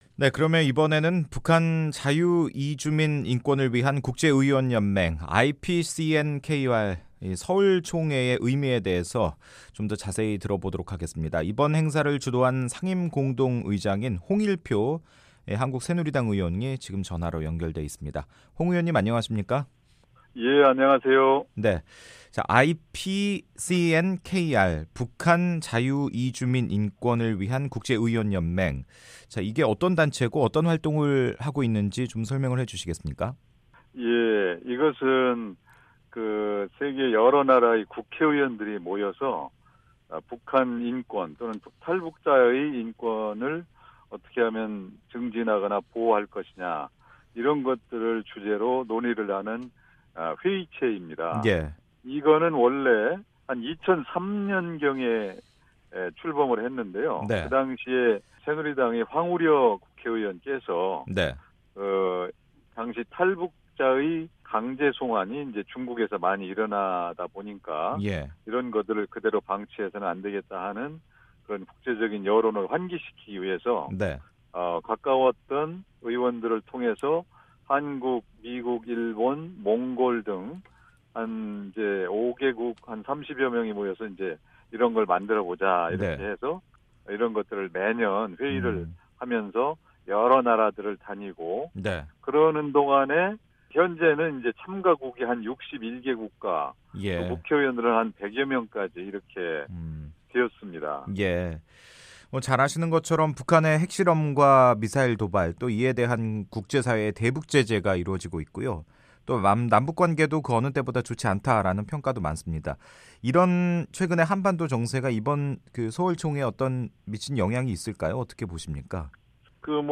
[인터뷰 오디오: 홍일표 북한인권 국제의원연맹 상임공동의장] 서울총회 배경과 의미